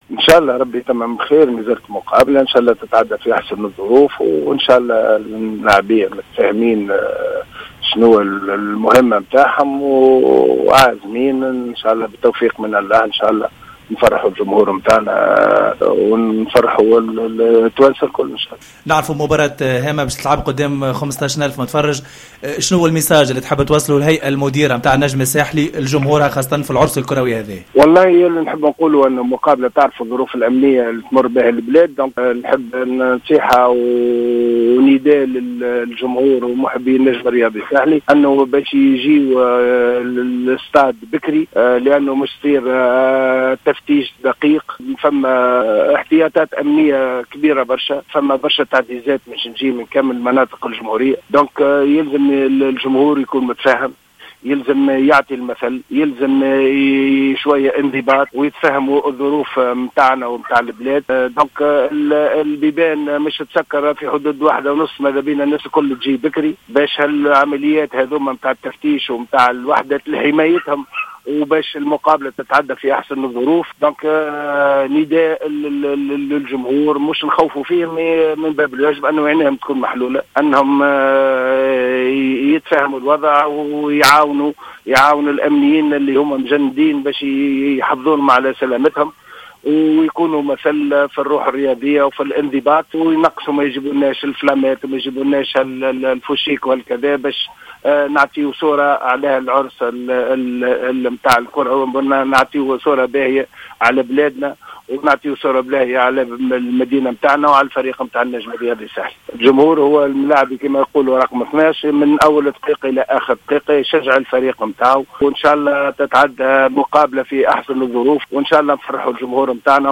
أشار رئيس النجم الساحلي رضا شرف الدين في مداخلة على جوهرة اف أم أنه في إطار الإجراءات الأمنية المشددة التي سيتم إعتمادها خلال تنظيم مباراة نهائي كأس الإتحاد الإفريقي لكرة القدم التي ستجمع يوم غد النجم الساحلي بأورلاندو بيراتس بداية من الساعة 15:00 بالملعب الأولمبي بسوسة فإنه على الجماهير الحضور إلى الملعب في توقيت مبكر لا يتجاوز موعد إغلاق الأبواب الذي سيكون في حدود الساعة 13:30.